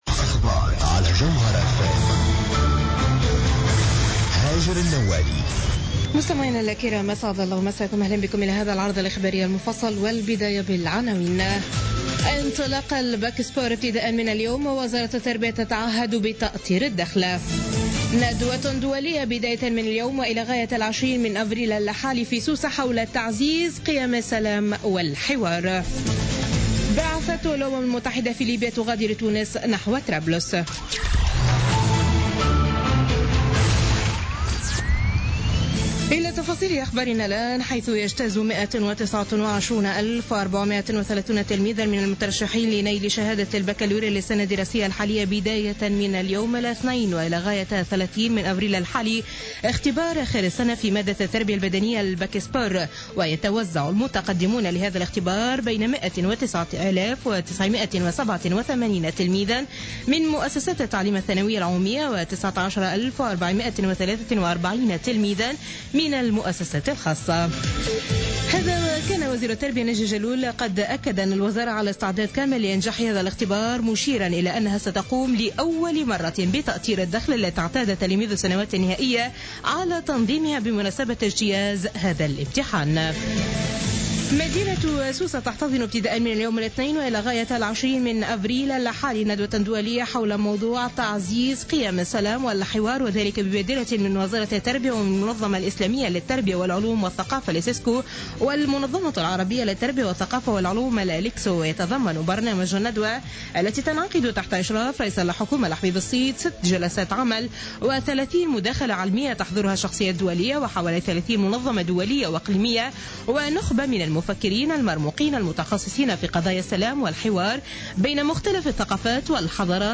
نشرة أخبار منتصف الليل ليوم الاثنين 18 أفريل 2016